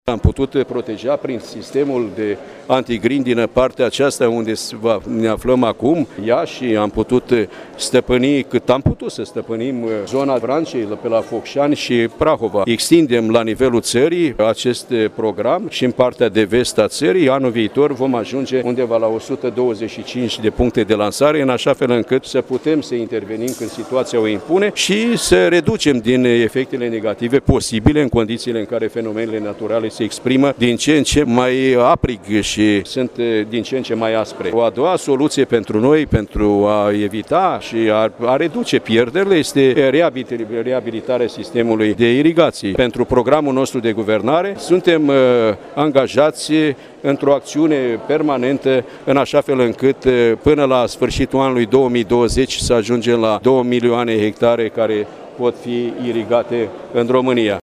Înaintea festivităţilor, ministrul agriculturii, Petru Daea, a avut o întrevedere cu fermierii din zonă, la care a abordat problematica fenomenelor meteorologice extreme care au afectat partea de vest a României în ultimele 24 de ore şi modalităţile de înlăturare a efectelor.
Petru Daea a declarat că s-au conturat măsuri pe termen scurt şi pe termen lung.